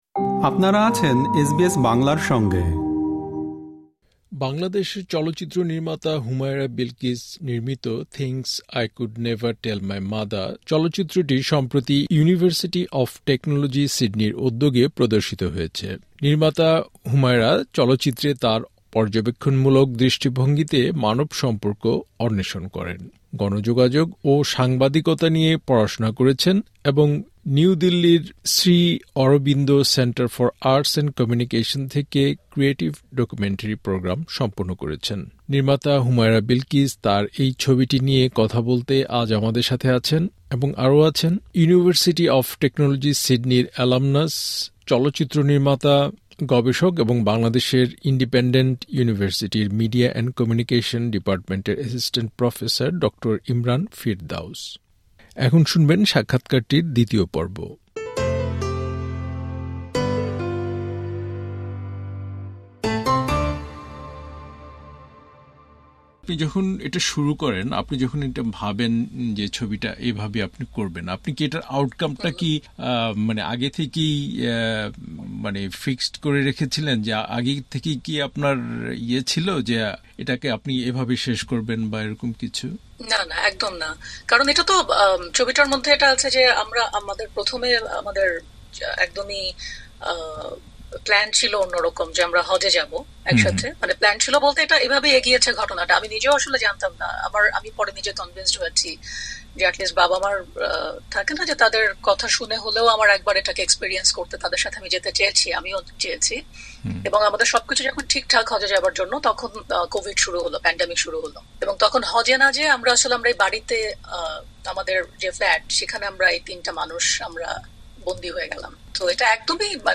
এখানে প্রকাশিত হলো তাদের সাথে সাক্ষাৎকারের দ্বিতীয় পর্ব, শুনতে ক্লিক করুন উপরের অডিও প্লেয়ারে।